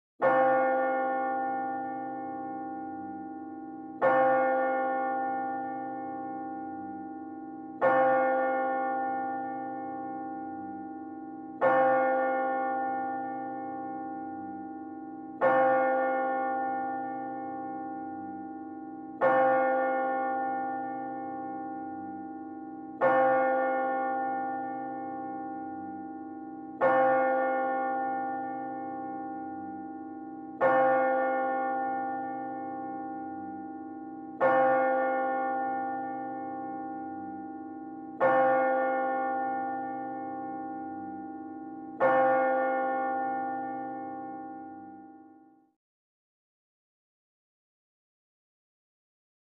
Courtroom Or Church; Large Mixed Crowd Murmurs Very Quietly In Large Wide Hall; Close To Distant Perspective.